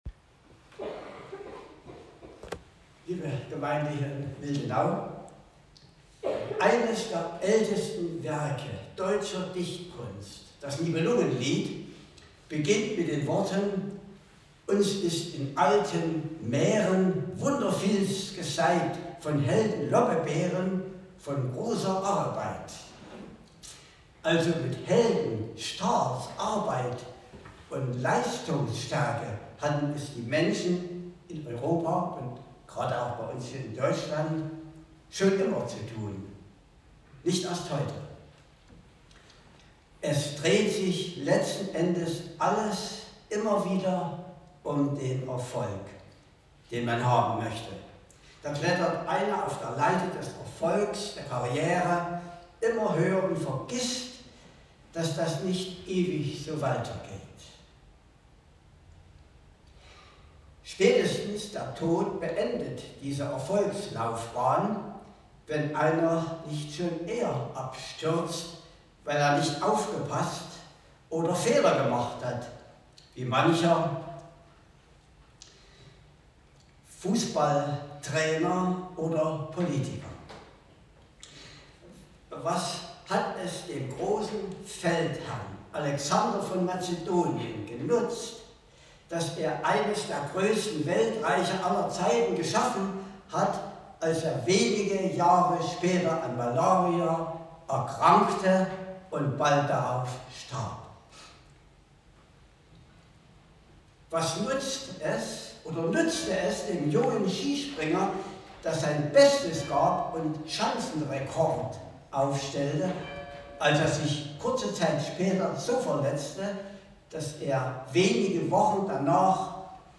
Passage: Johannes 15; 1-8 Gottesdienstart: Predigtgottesdienst Wildenau